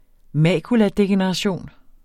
Udtale [ ˈmæˀkulaˌdegεnəʁɑˌɕoˀn ]